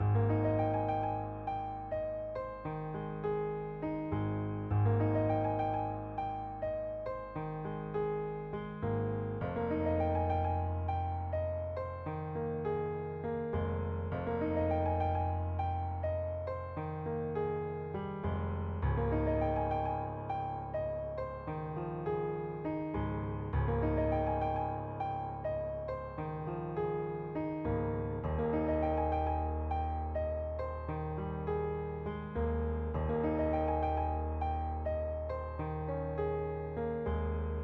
Tag: 178 bpm Drum And Bass Loops Drum Loops 232.79 KB wav Key : Unknown